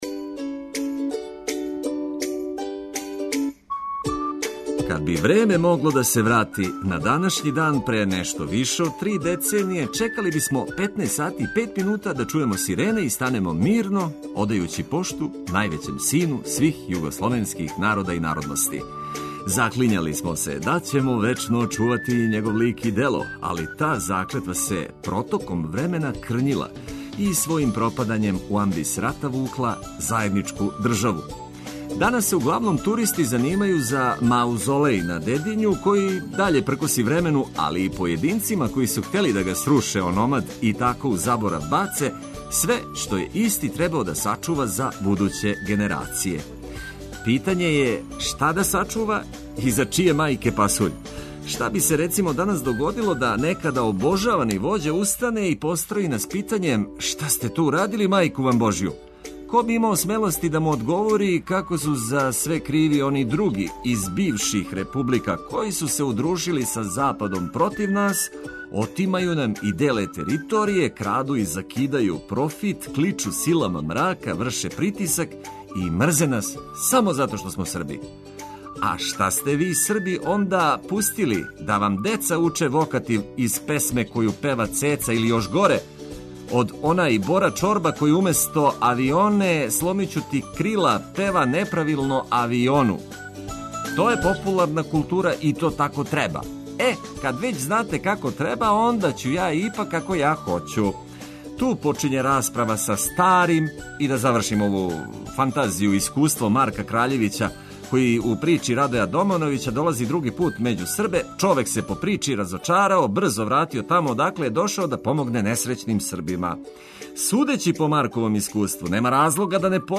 Најавићемо викенд трошећи јутро уз добру музику и важне вести.